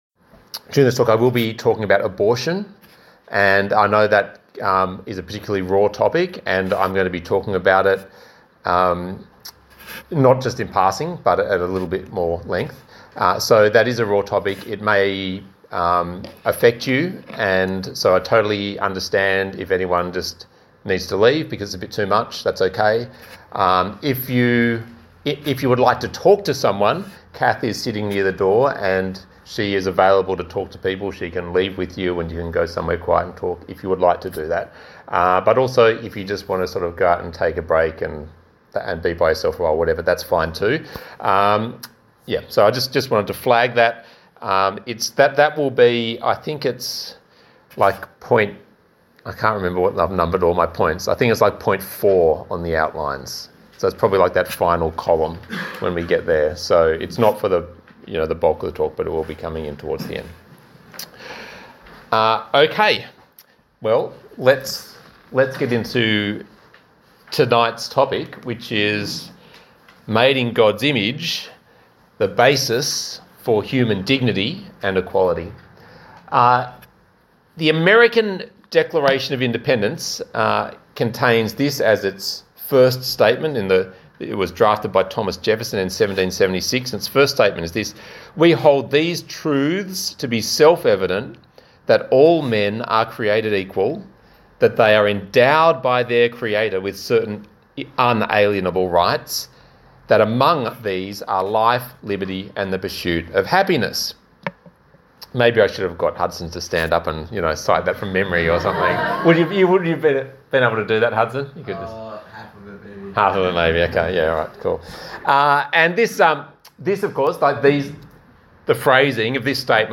Talk Type: Bible Talk Topics: creation , dignity , equality , humanity , image of God